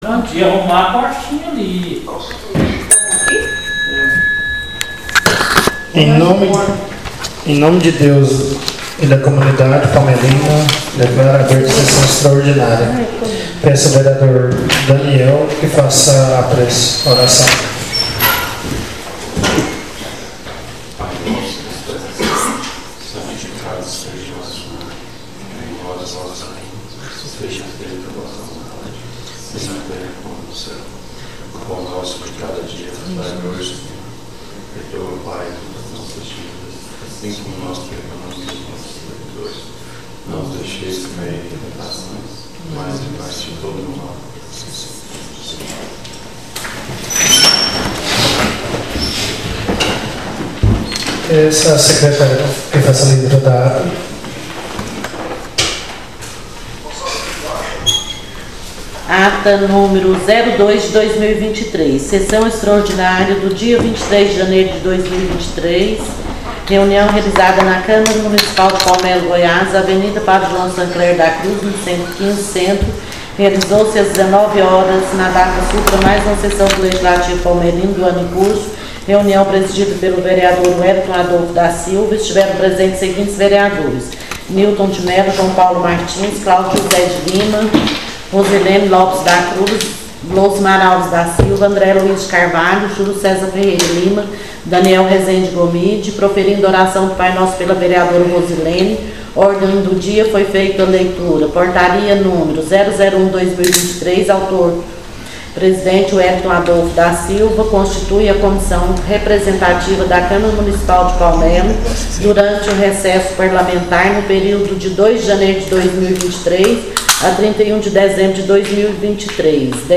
SESSÃO EXTRAORDINÁRIA 24/01/2023